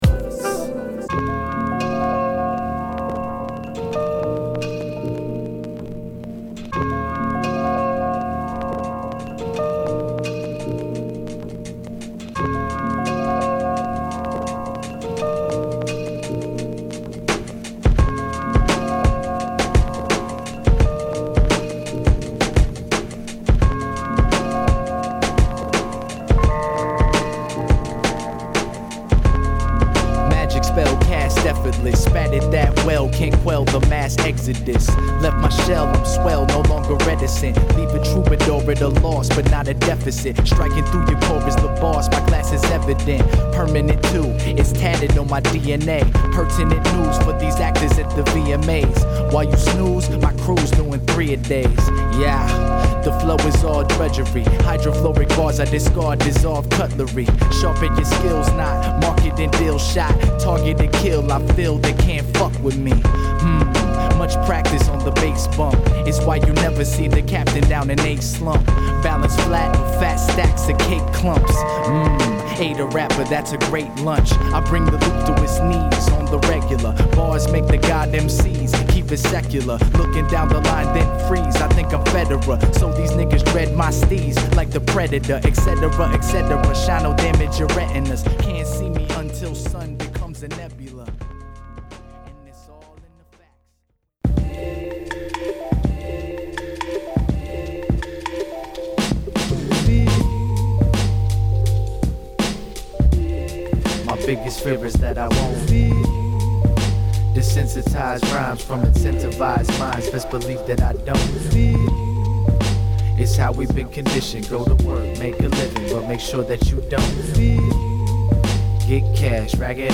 アルバム通してジャジーな仕上がり